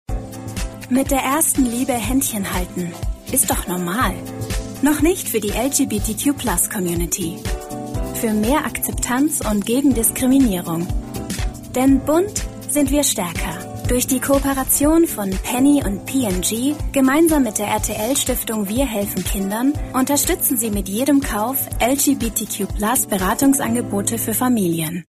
Spot